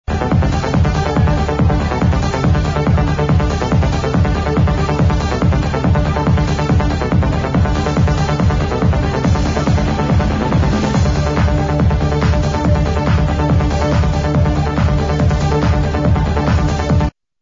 heres the other tune from homelands 99 im tryin to id